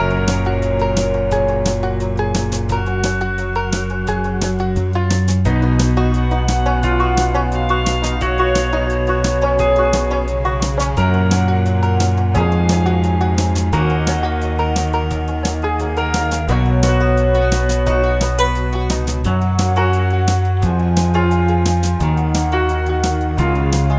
no Backing Vocals Country (Female) 3:27 Buy £1.50